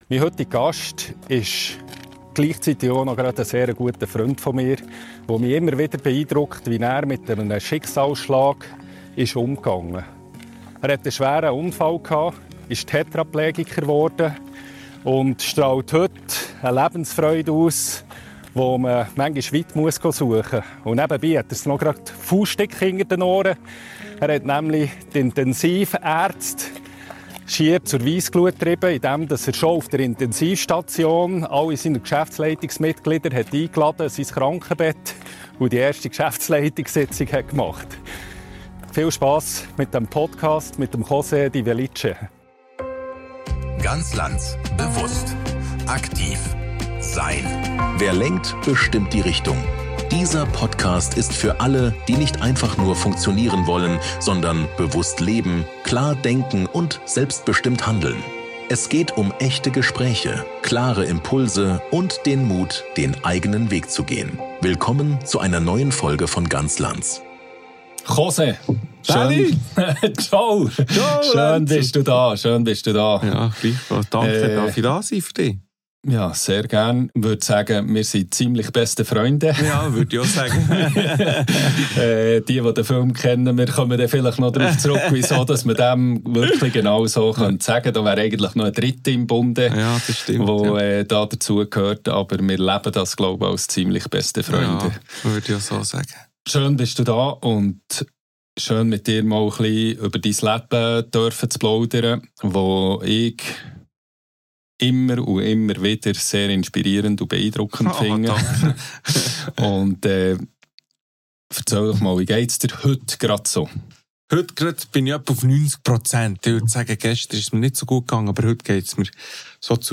Ein bewegendes Gespräch über Verlust, Identität, Selbstführung – und den klaren Entscheid, weiterzugehen.